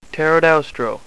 Pronunciation Key
ter-o-DOW-stro